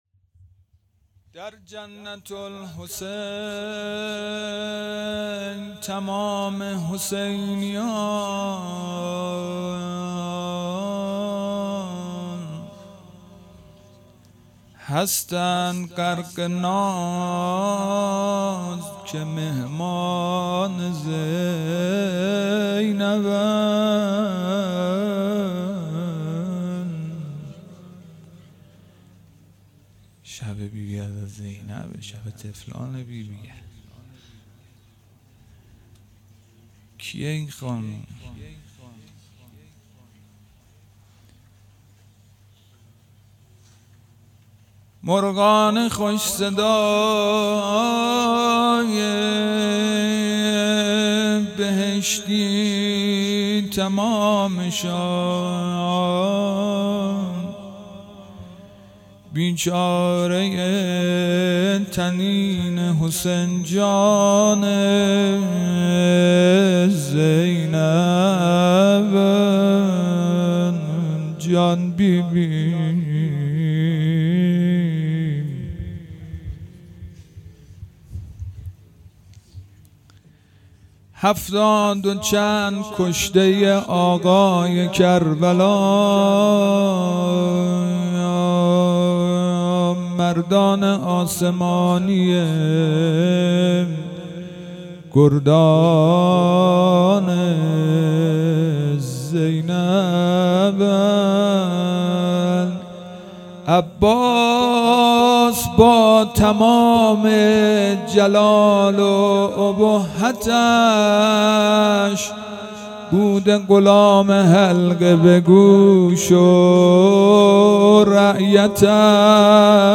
شب چهارم محرم الحرام 1441